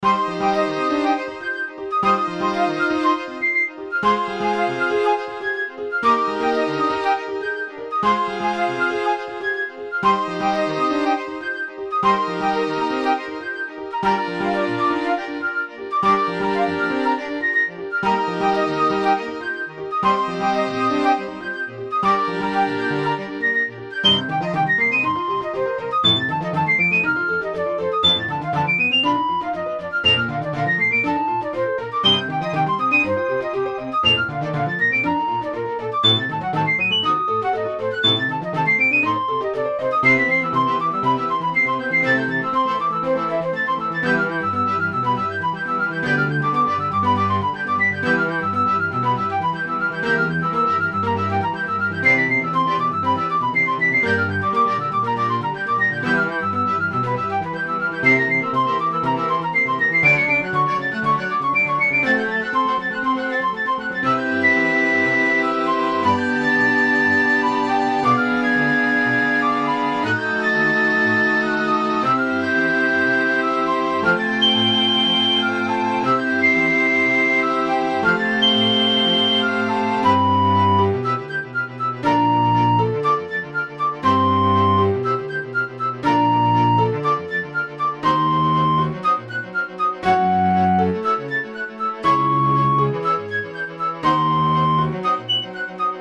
フルート、ピアノ、ビオラ、チェロ